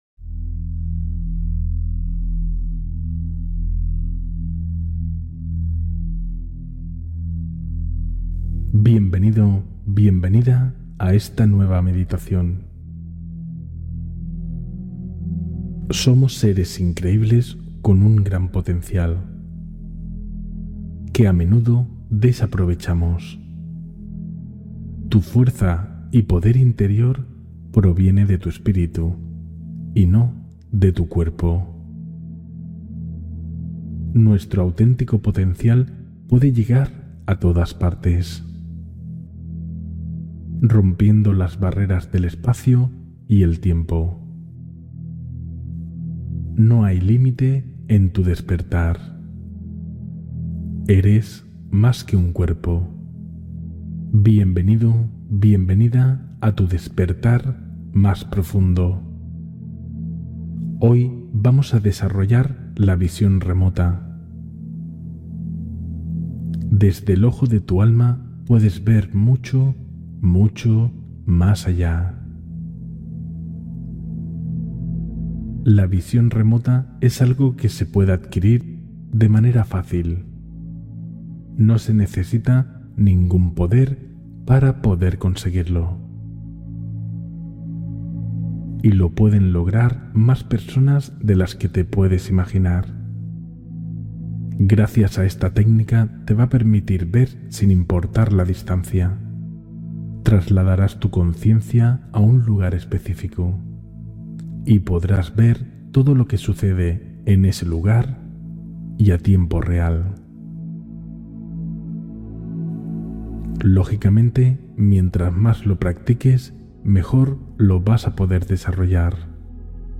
Entrena la Percepción Interna: Meditación para Ampliar la Sensibilidad Consciente